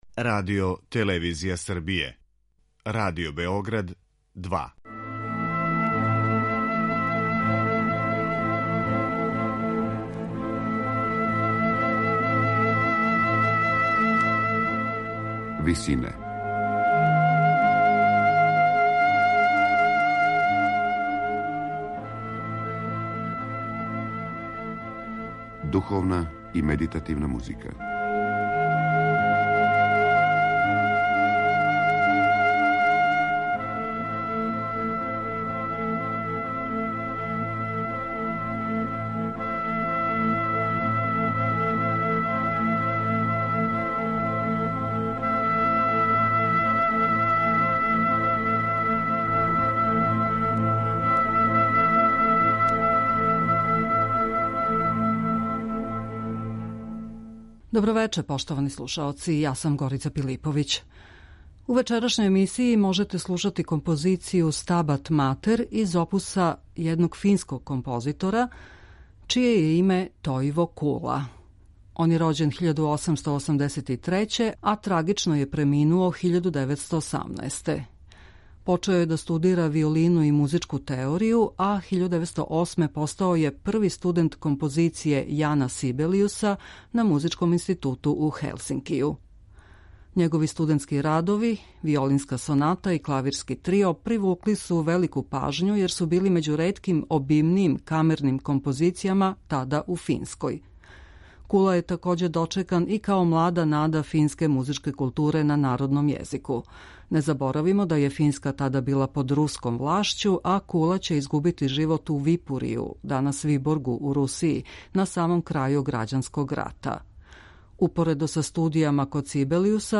медитативне и духовне композиције аутора свих конфесија и епоха